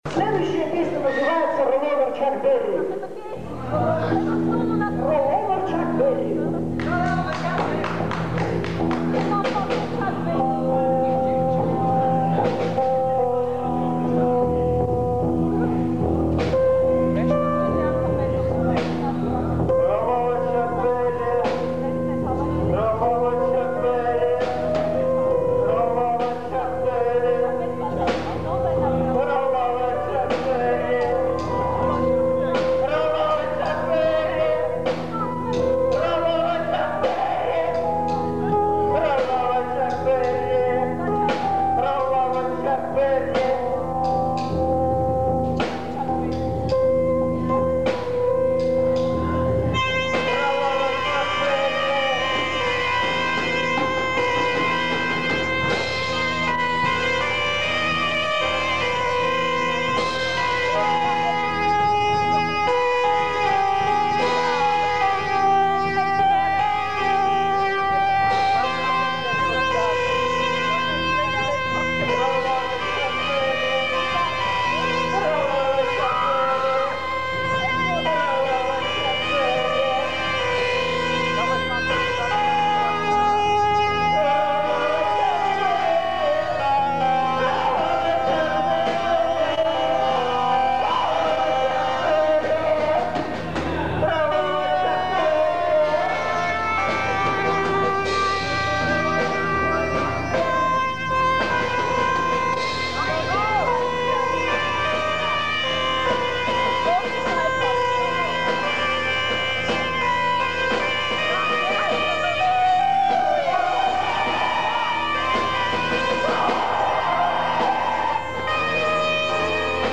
(концертник)